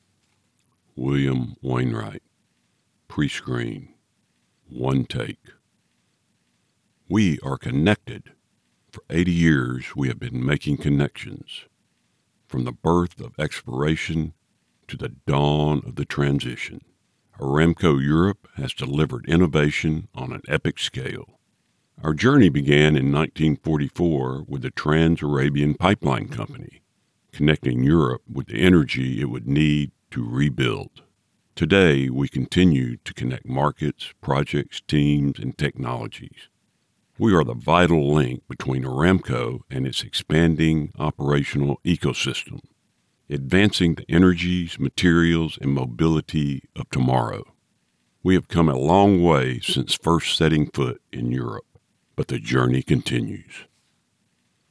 Audition
Deep powerful southern voice
Middle Aged